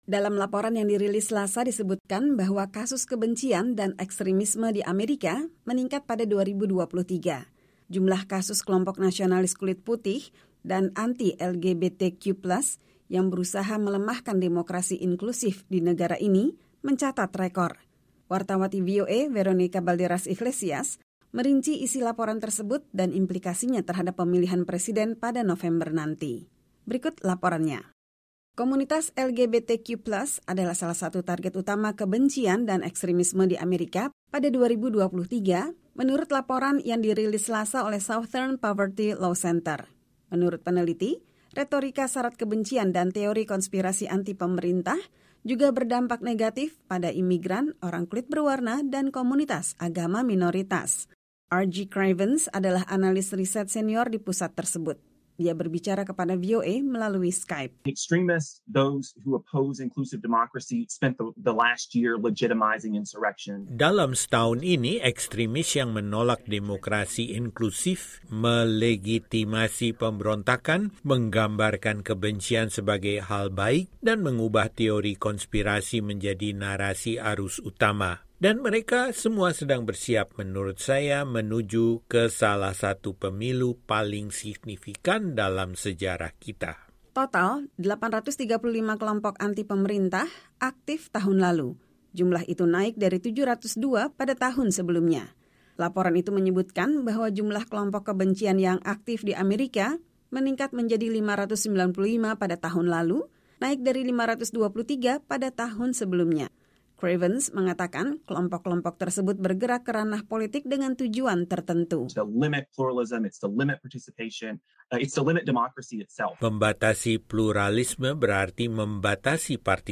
Dia berbicara kepada VOA melalui Skype.